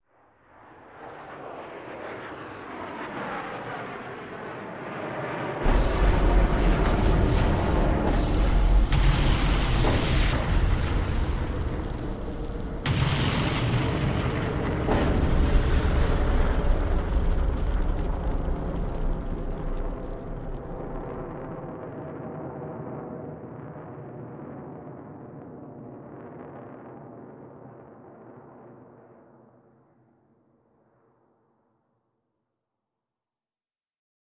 Fighter jet drops exploding bombs. Incoming, Explosion War, Explosion Explode, Bomb